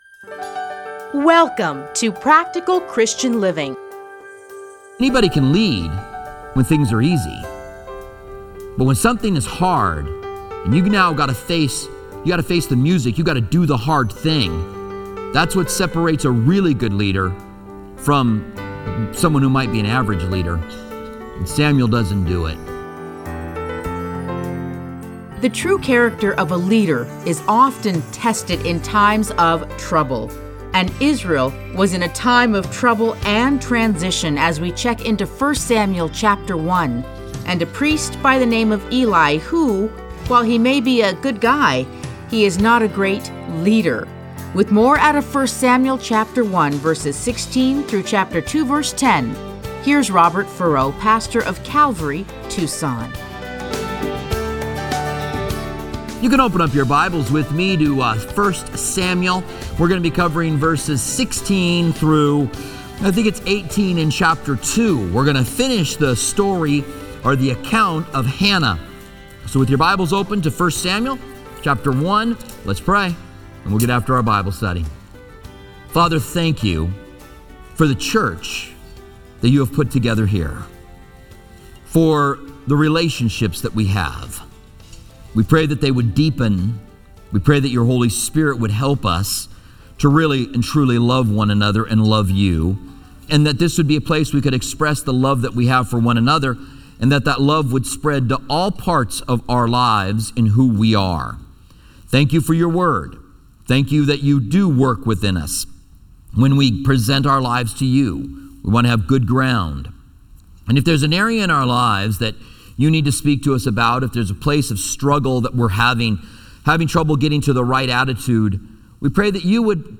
Listen to a teaching from 1 Samuel 1:16-2:10.